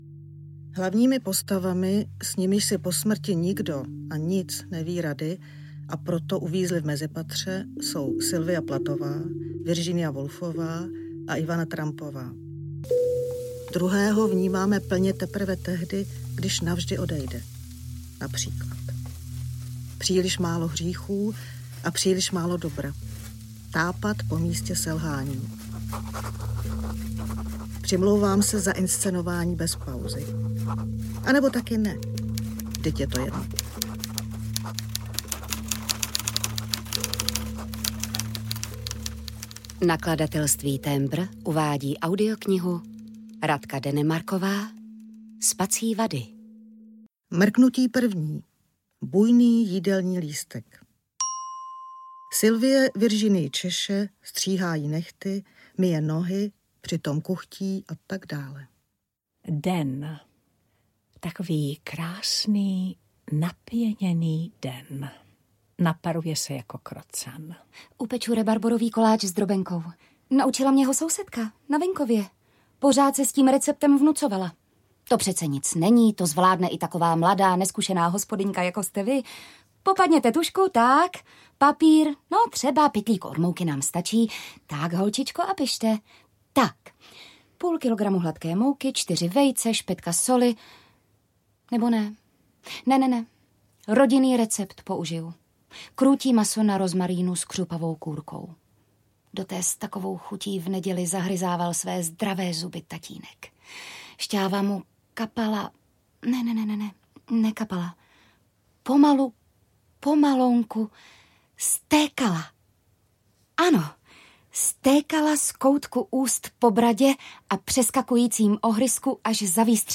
Spací vady audiokniha
Ukázka z knihy
Hra Spací vady byla inscenována ve výrazně zkrácené verzi, tato audioverze ji čtenářům představuje v úplnosti.